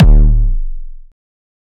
EDM Kick 22.wav